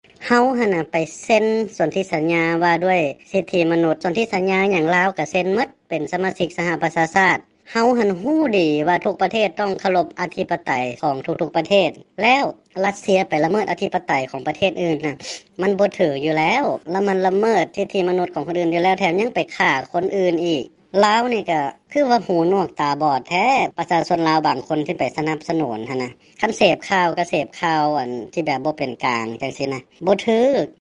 ສຽງ 1 ພະນັກງານອົງການສາກົນກ່າວກ່ຽວກັບການລົງຄະແນນສຽງຂອງລາວ ໃນ ສປຊ